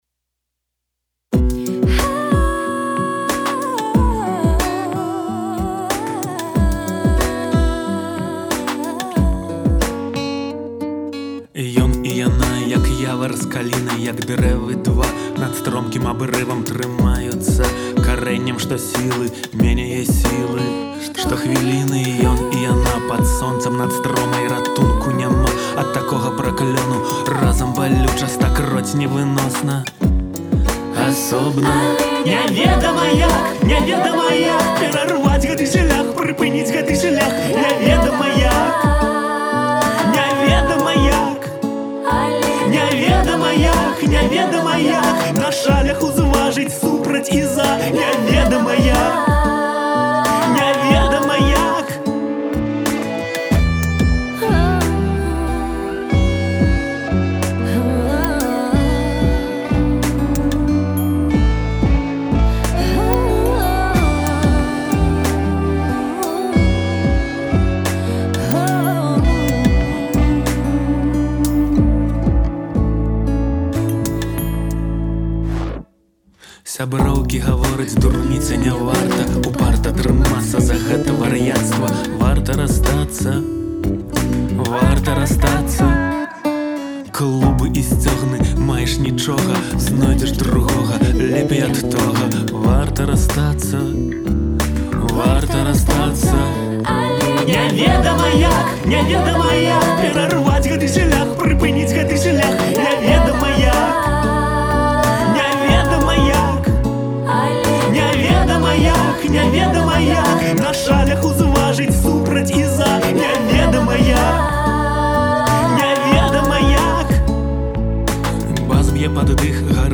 поп-музыкаю